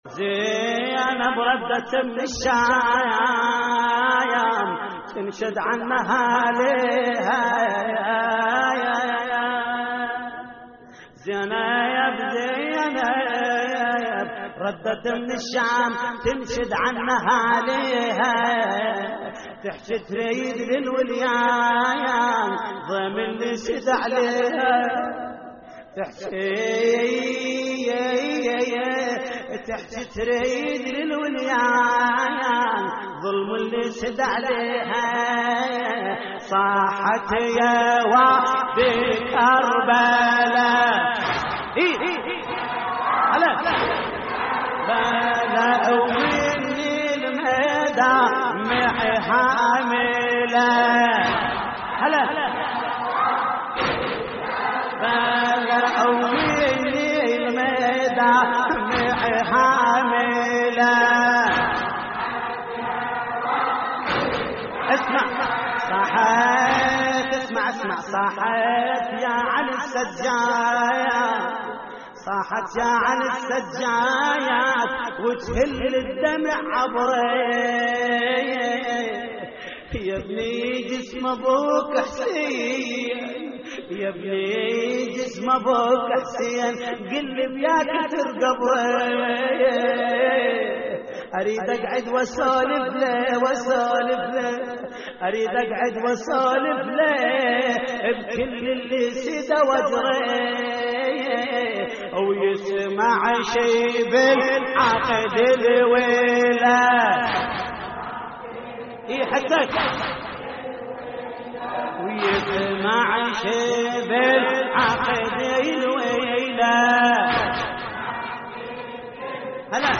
هوسات لحفظ الملف في مجلد خاص اضغط بالزر الأيمن هنا ثم اختر